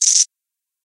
PixelPerfectionCE/assets/minecraft/sounds/mob/silverfish/say4.ogg at mc116